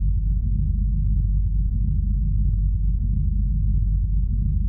electronicDroneHumLoop.wav